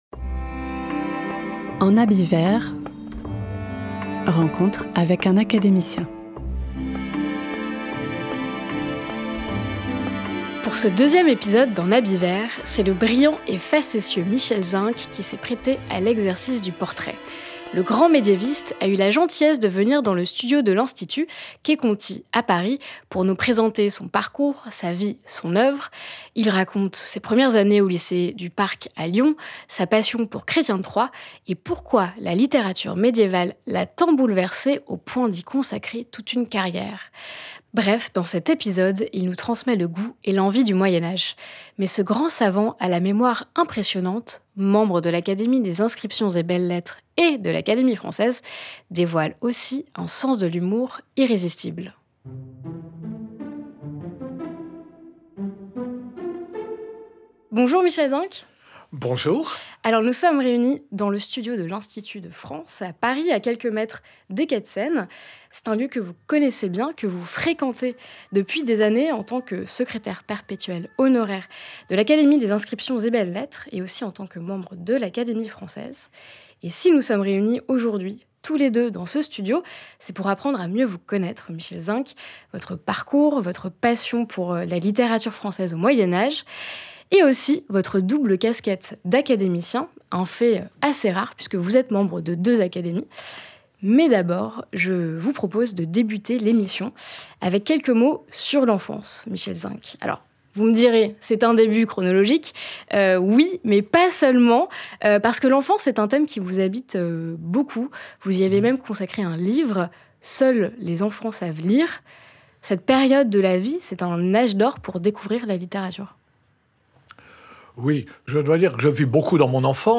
Pour ce deuxième épisode d'En Habit Vert, c’est Michel Zink, brillant et facétieux médiéviste, qui a accepté de se prêter à l’exercice du portrait.
Depuis le studio de l’Institut, Quai Conti à Paris, il retrace son parcours, évoquant ses années au Lycée du Parc à Lyon, sa passion pour Chrétien de Troyes, et sa rencontre avec la littérature médiévale qui l’a bouleversé au point de lui consacrer toute sa carrière.
Derrière ce grand savant, Secrétaire perpétuel honoraire de l'Académie des inscriptions et belles-lettres et membre de l'Académie française, se révèle aussi dans le rire un homme d'esprit et d'humour, rendant cet échange aussi savoureux qu'instructif.